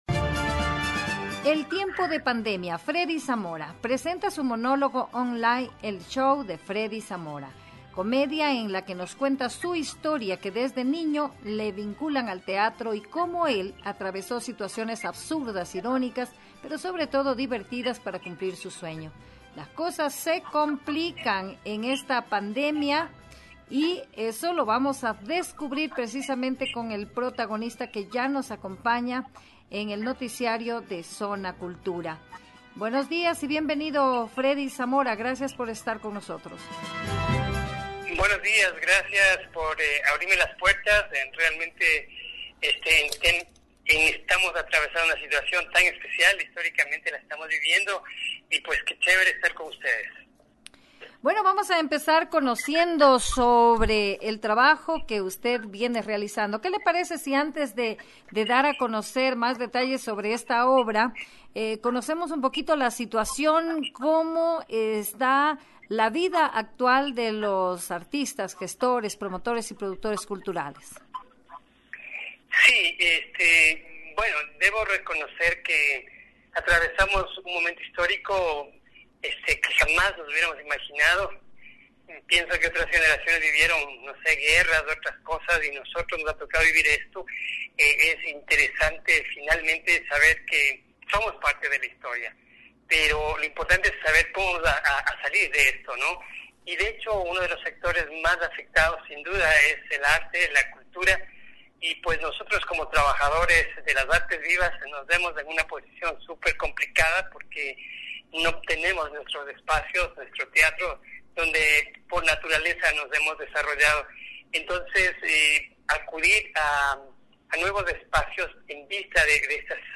fue entrevistado por Zona Cultura para ampliar detalles de esta invitación.